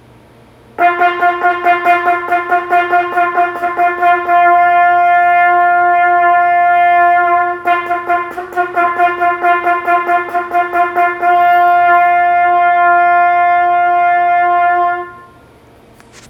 一つ二つタンギングするにはあまり問題はないのですが、連続してのシングルタンギングとなると、舌がもつれたような、詰まったような音色になります。
ここで私の音でうまくタンギングできていない時の例を参考にしてください。
【つまったタンギング】
タンギング間違い-C01.wav